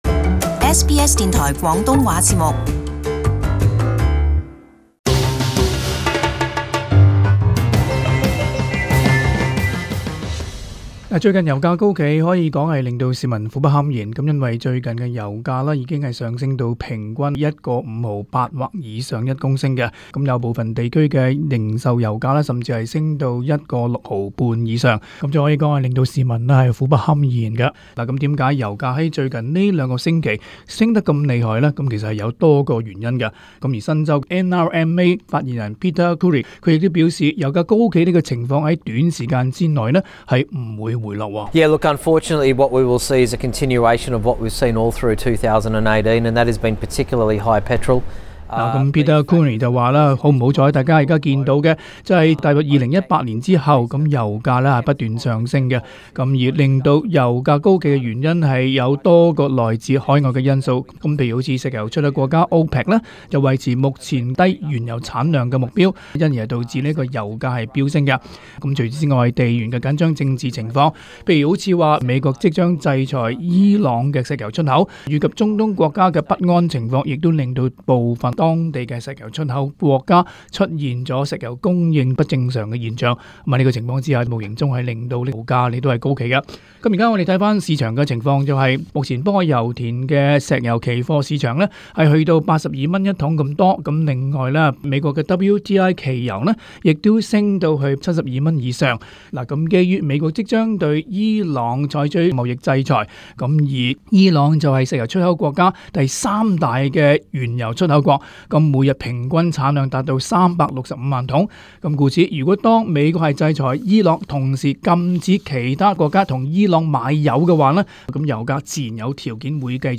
【时事报导】油价高企的原因与市民的反应